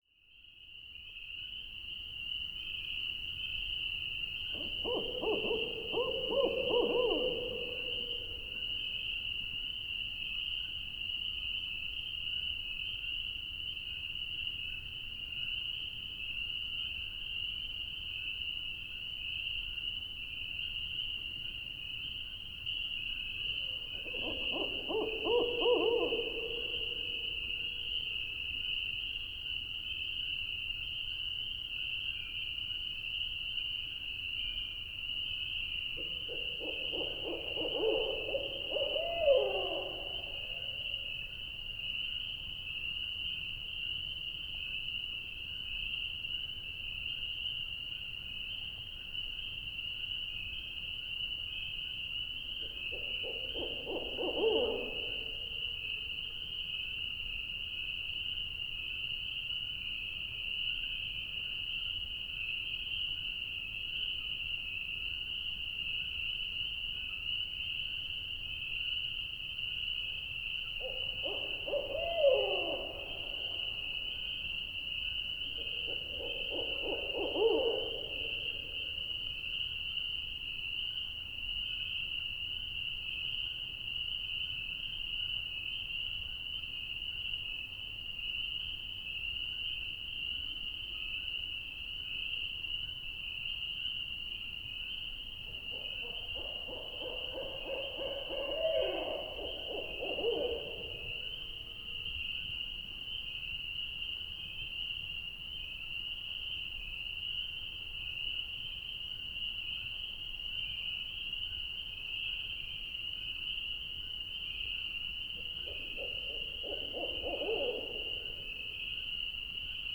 I’m sure glad I stopped at that particular spot and was able to document all the hooting, with only Spring Peepers sounding off in the background (thank goodness a jet didn’t fly over in the middle of it all). This is among my favorites for its meditative effect.
NOTE: This recording has been edited to remove loud pings from my car (given as it cooled down) and to smooth out the volume of the spring peepers (I had to move three or four times to reduce the volume of the peepers, which was overwhelming at first). Although edited, the recording faithfully conveys the resonant hoots of the owls.